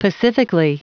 Prononciation du mot pacifically en anglais (fichier audio)
Prononciation du mot : pacifically